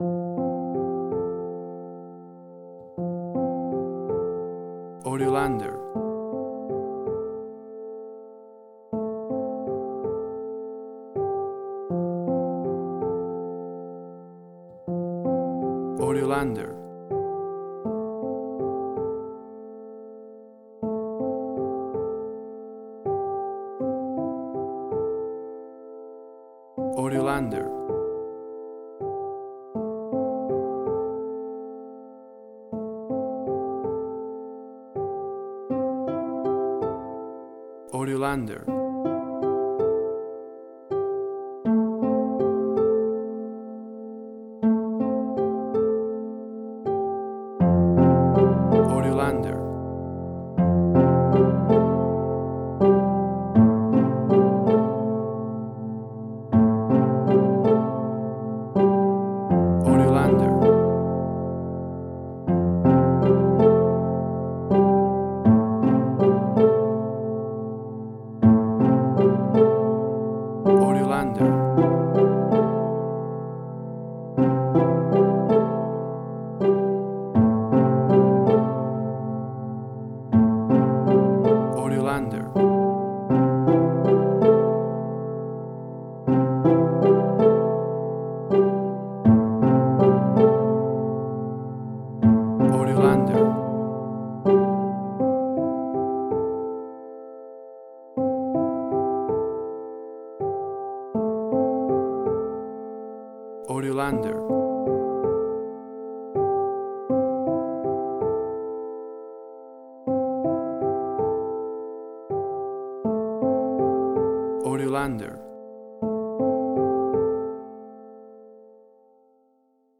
Suspense, Drama, Quirky, Emotional.
Tempo (BPM): 80